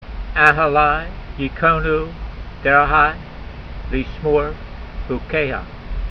Diphthongs: Pronounce with an “eye” sound (“ai” as in aisle) a consonant with a patack or kawmetz under it when it is followed by the yud.